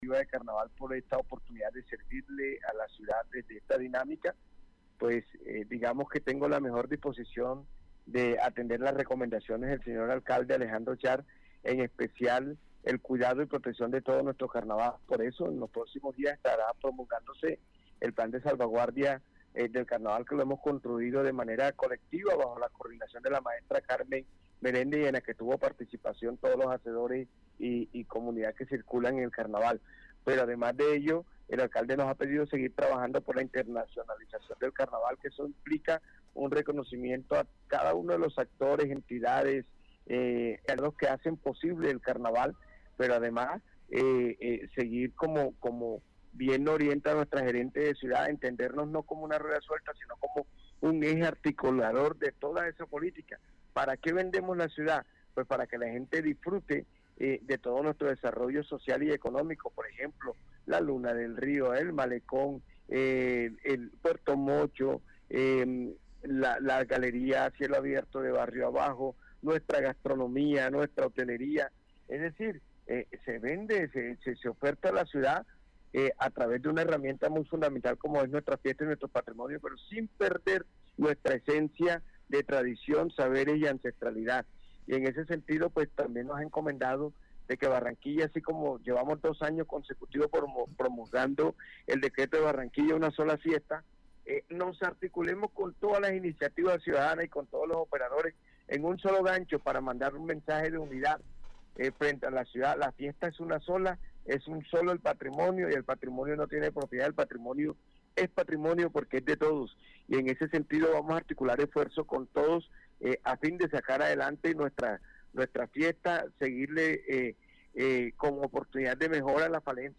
En entrevista con Noticias Ya, el director de Carnaval S.A.S., Juan Ospino, habló sobre los retos de la organización, el fortalecimiento del papel del Distrito en la fiesta y las metas de cara al Carnaval 2027. El funcionario reiteró su compromiso con la protección del patrimonio cultural y con la articulación institucional para consolidar el evento como eje del desarrollo social y económico de Barranquilla.